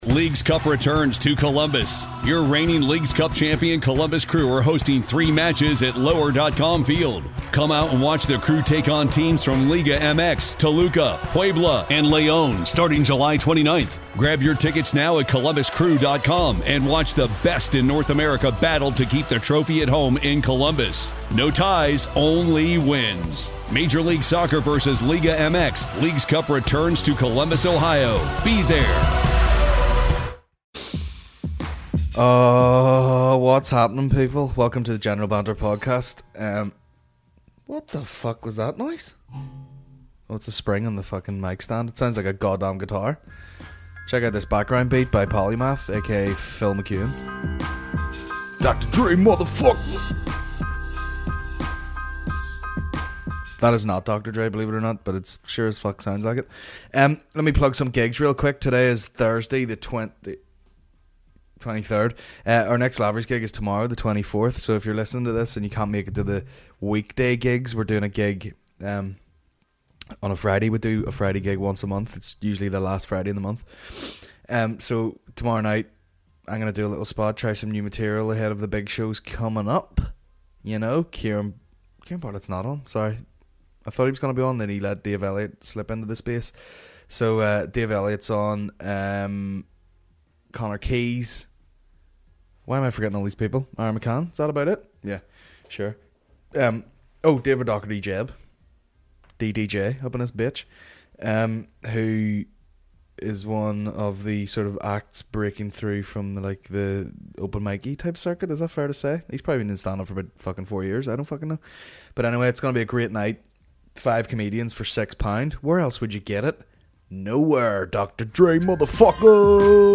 Thursday 23rd November 2017 General Banter Podcast General Banter Podcast Comedy 4.8 • 1.1K Ratings 🗓 23 November 2017 ⏱ 98 minutes 🔗 Recording | iTunes | RSS 🧾 Download transcript Summary This week - Inappropriate Hugs.